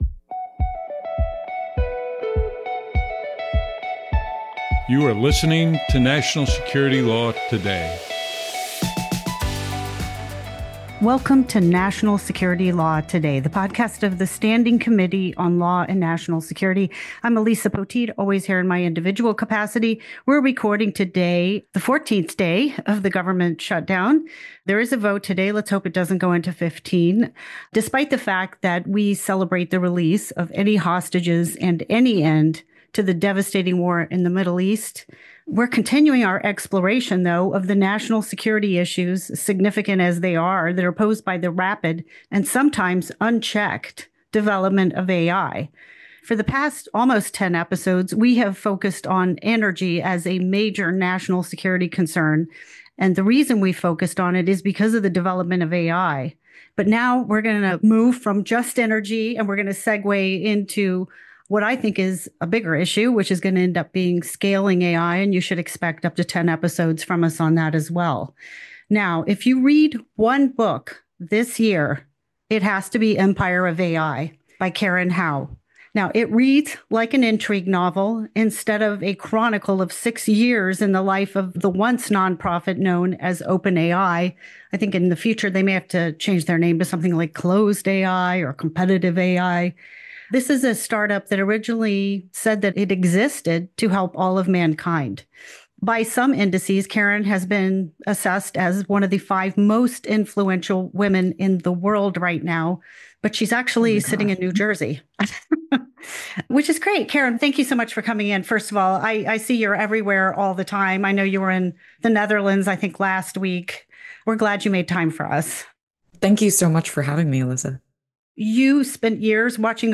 National Security Law Today brings legal experts discussing the hot topics and current issues in the world of national security law right to your phone.